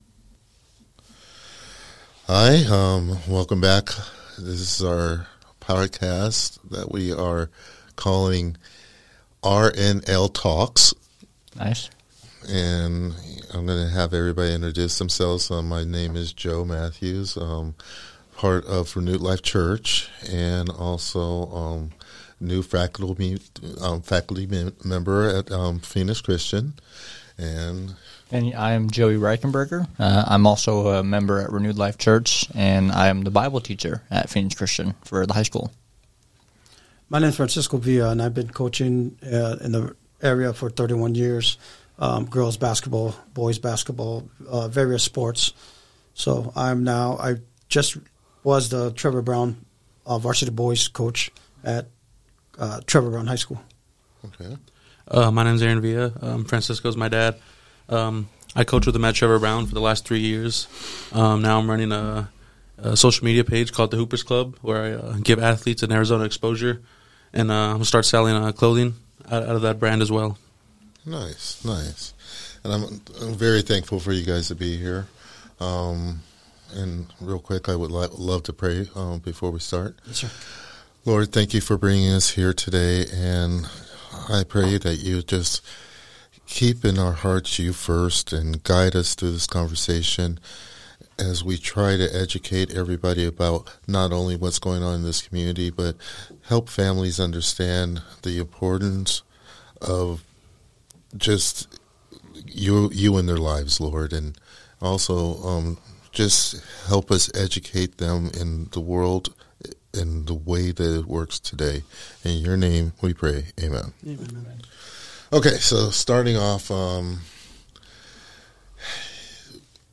This conversation is real, relevant, and rooted in the belief that sports can be more than just a game — it can be a calling.